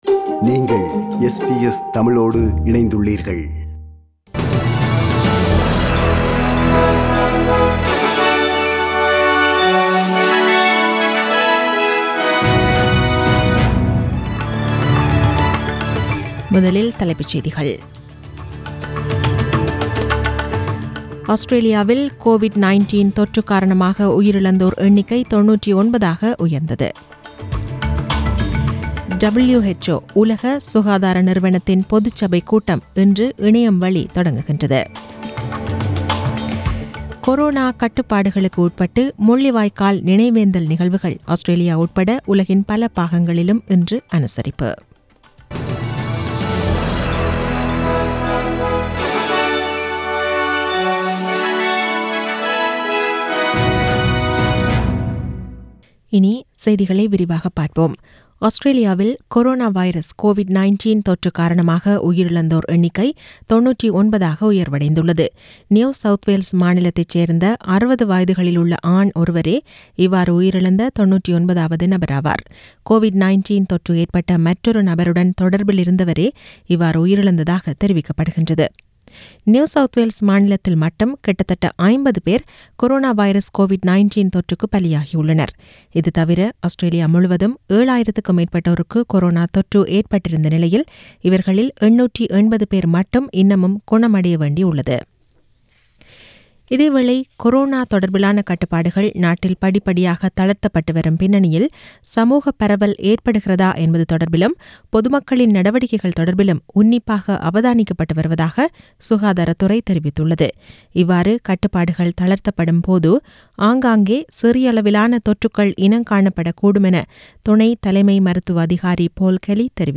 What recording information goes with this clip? The news bulletin was aired on 18 May 2020 (Sunday) at 8pm.